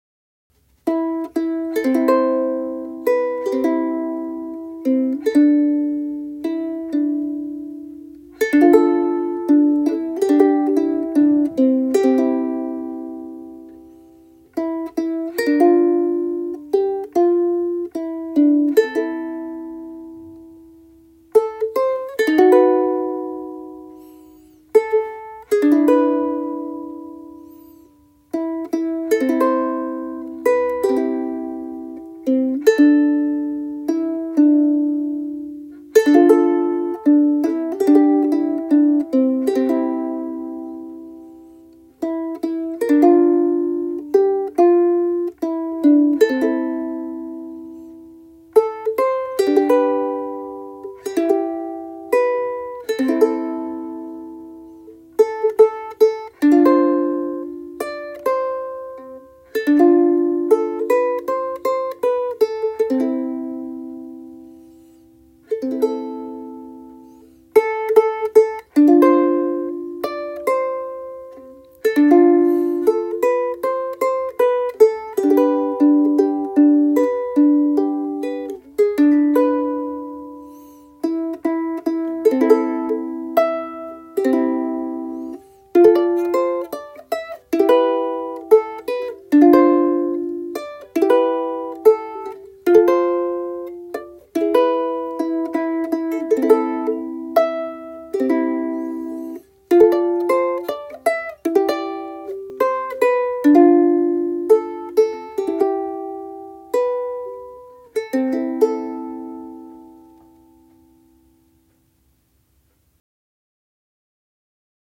こちらがそのデモ演奏です。
とてもあたたかみのある音で、音量もサスティンもバランスも良く、ウクレレ材として実力十分。